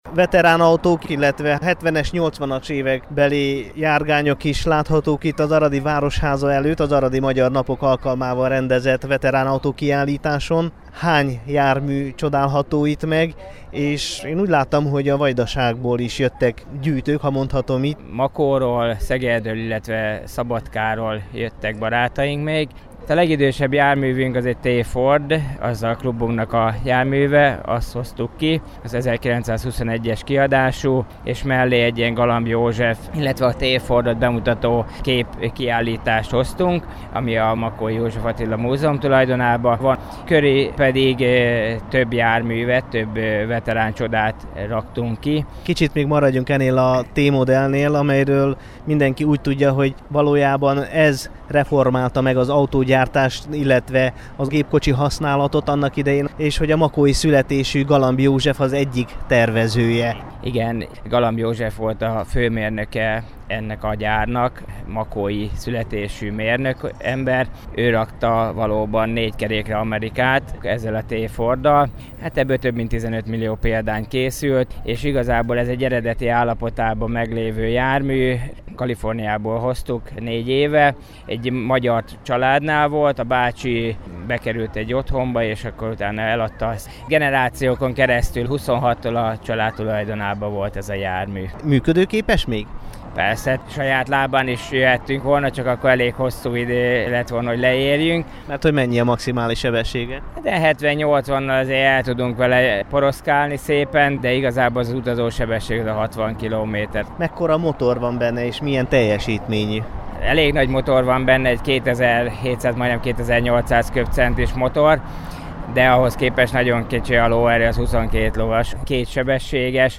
makoi_veteran_autosklub.mp3